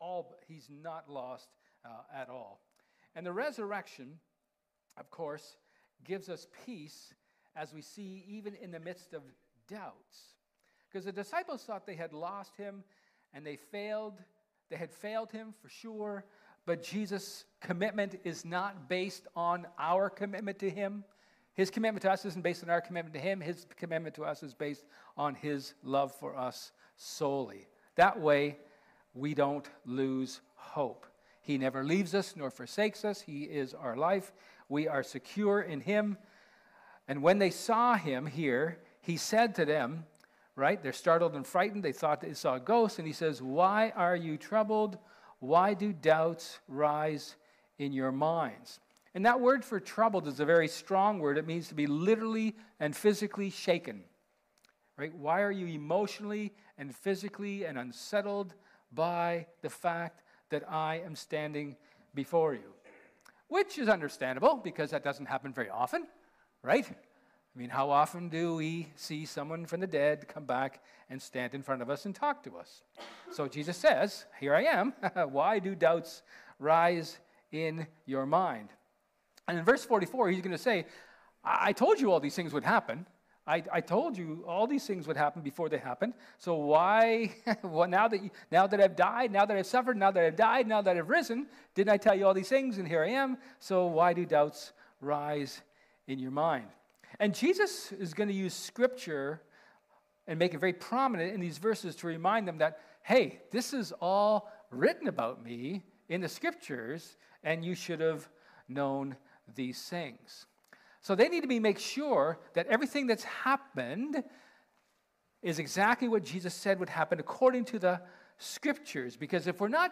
Service Type: Sermon
April-5-2026-sermon.mp3